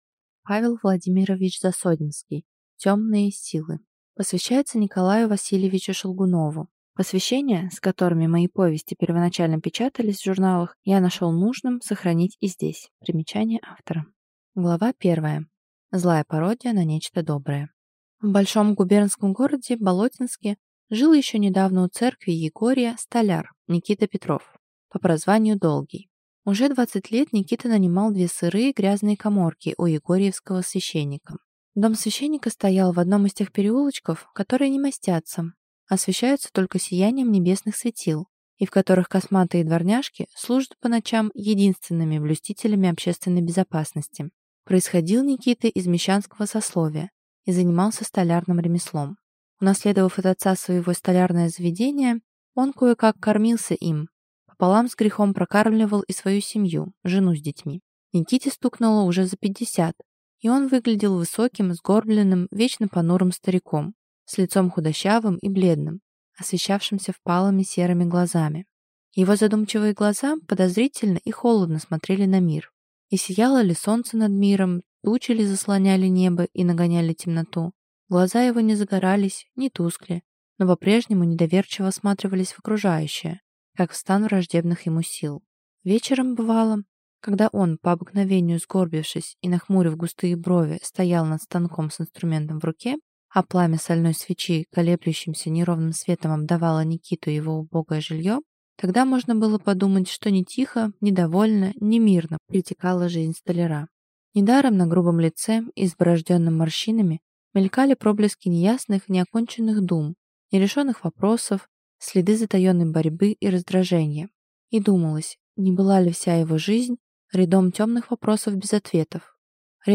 Aудиокнига Темные силы Автор Павел Владимирович Засодимский
Прослушать и бесплатно скачать фрагмент аудиокниги